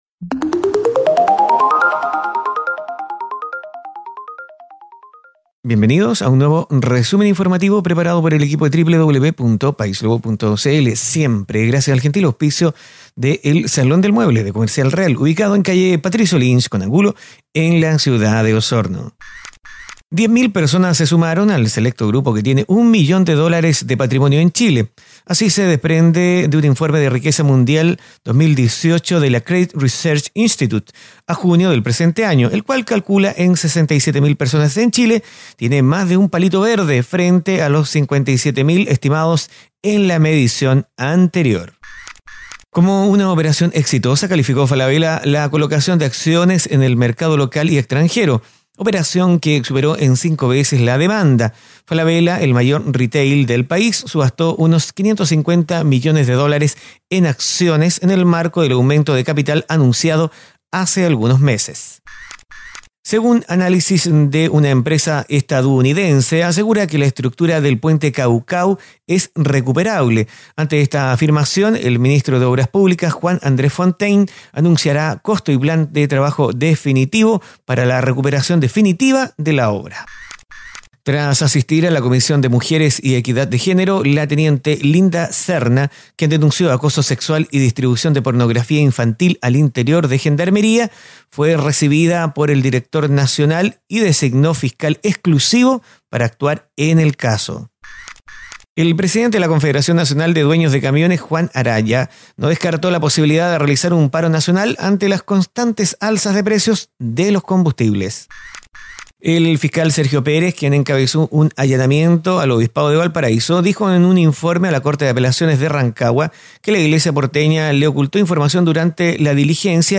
Resumen Informativo - Viernes 19 de octubre de 2018
🔴 Noticias en pocos minutos.